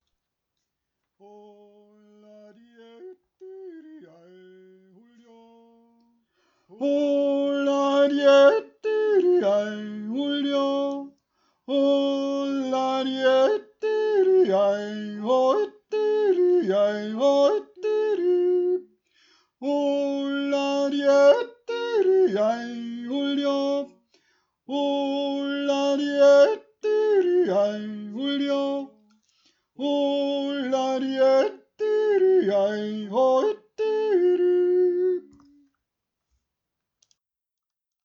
2. Stimme